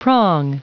Prononciation du mot prong en anglais (fichier audio)
Prononciation du mot : prong